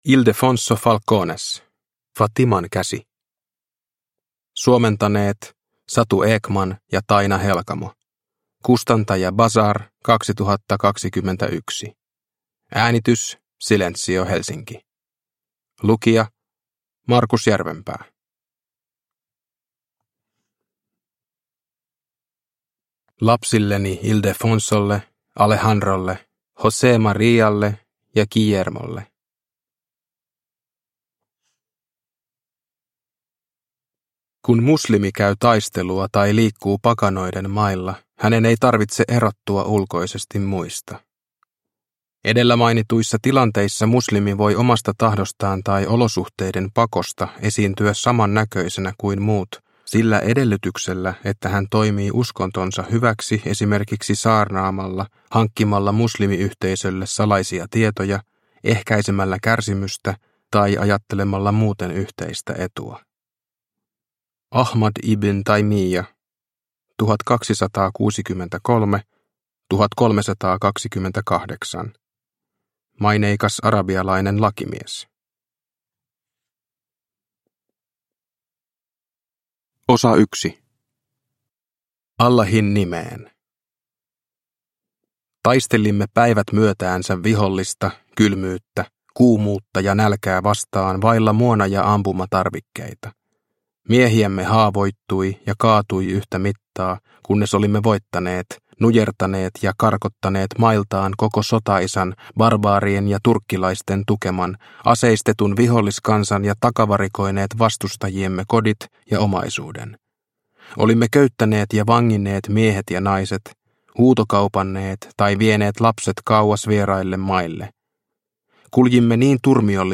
Fatiman käsi – Ljudbok – Laddas ner